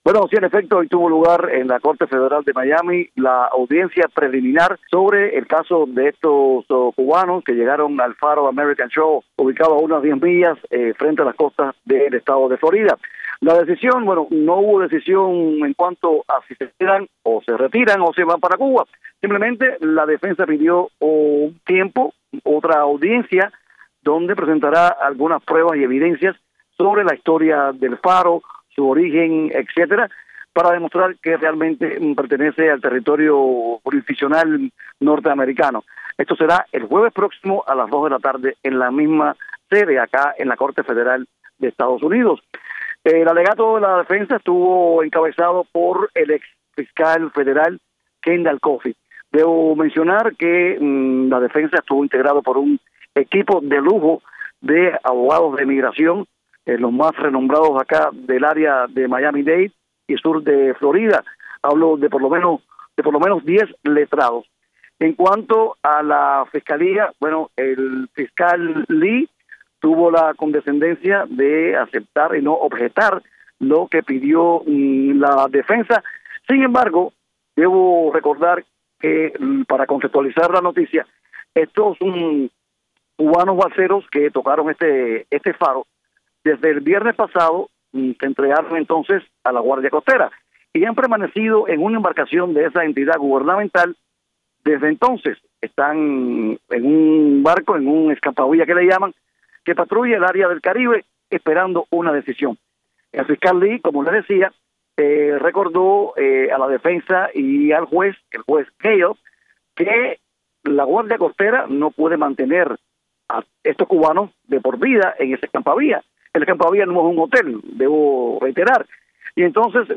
Desde la corte federal de Miami